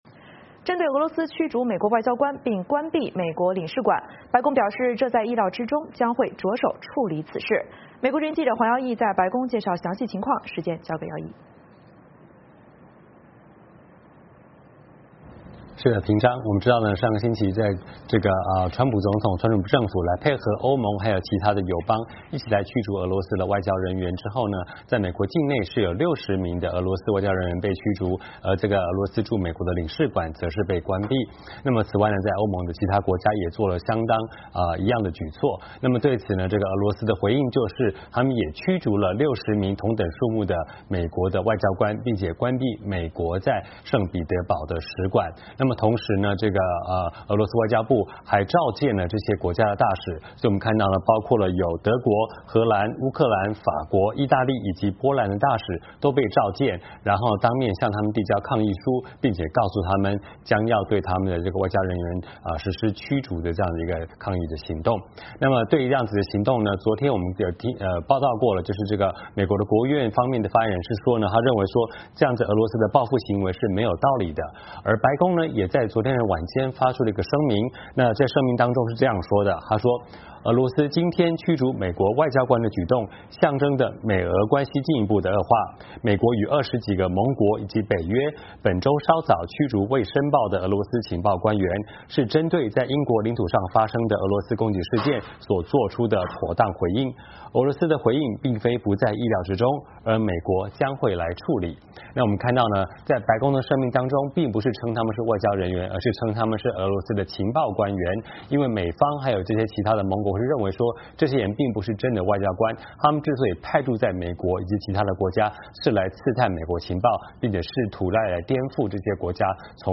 白宫 —